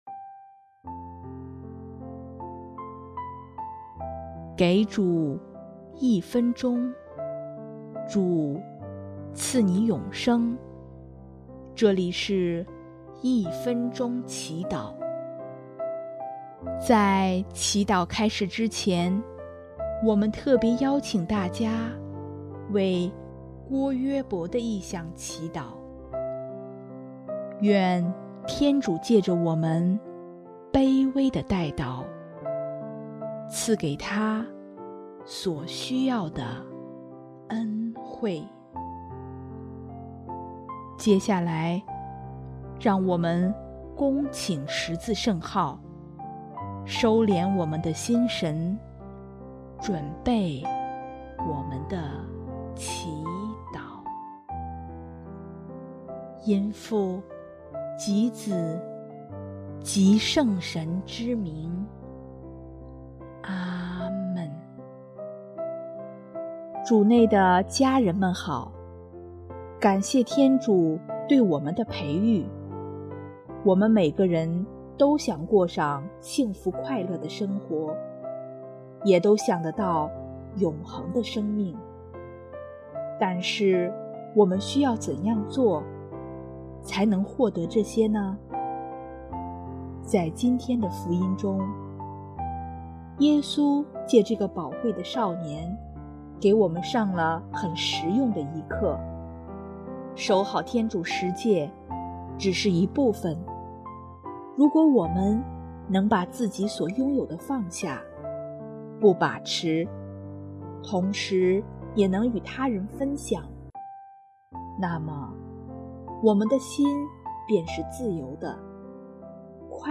【一分钟祈祷】|8月21日 主在我内，迈向永恒的幸福